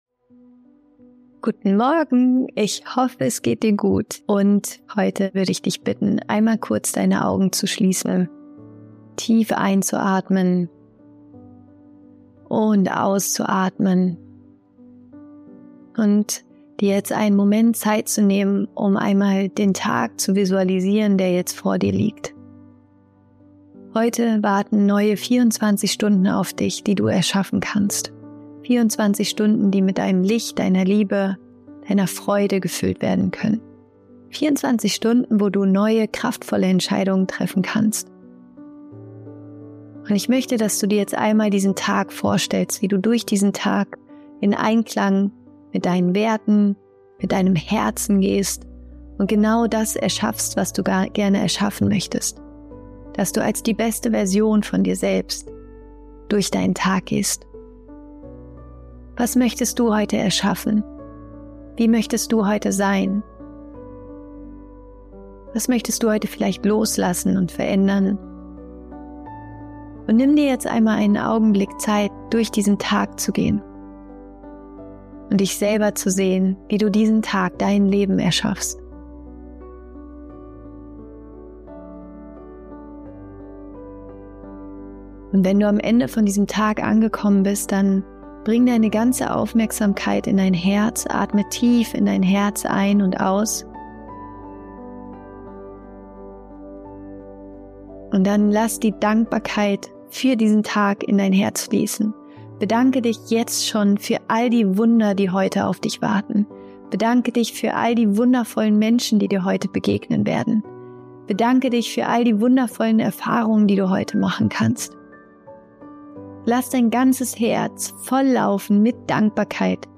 In dieser kurzen Rise Up & Shine Voice Message nehme ich dich mit in eine kleine Morgen-Visualisierung, die dich dabei unterstützt, deinen Tag mit Liebe, Freude und Dankbarkeit zu erschaffen.